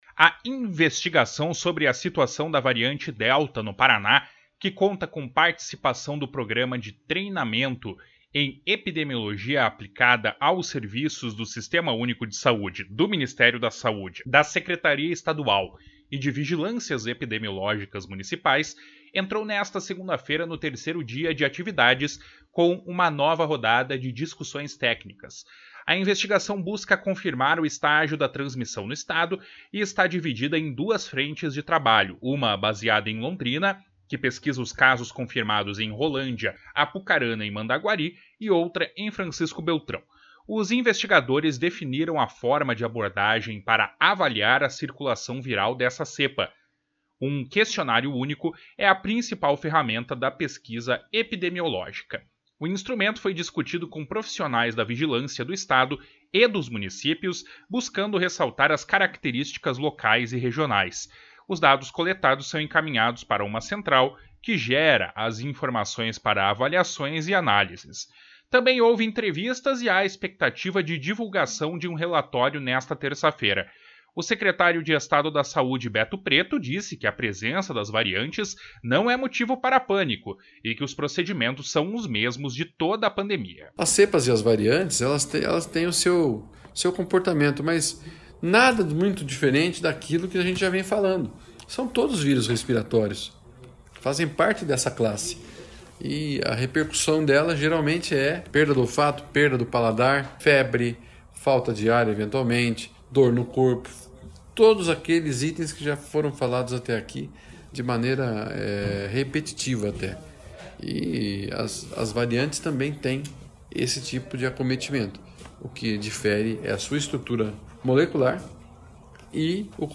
O secretário de Estado da Saúde, Beto Preto, disse que a presença das variantes não é motivo para pânico e que os procedimentos são os mesmos de toda a pandemia. // SONORA BETO PRETO // Beto Preto ainda reforçou que a população de todo o Paraná deve manter as medidas preventivas.